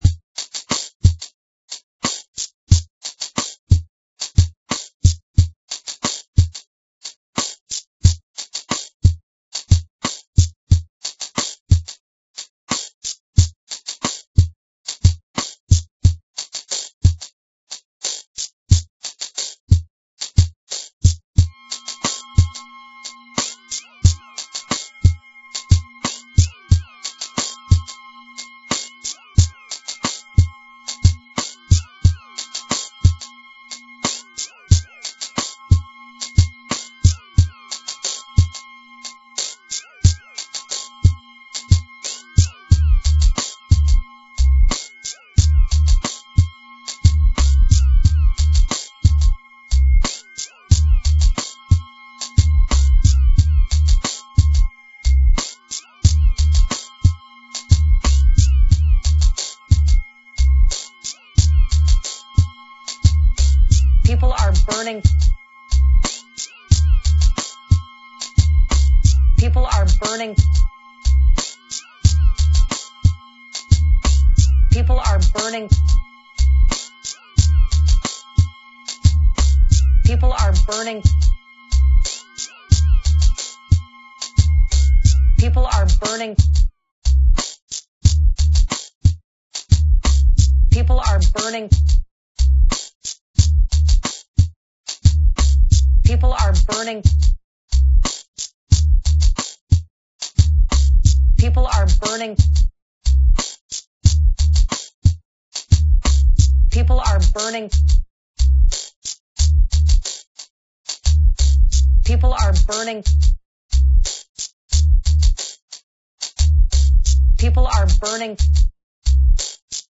dance/electronic
Trip Hop intro, Break Beat outro-with surprise lyrics.
Trip-hop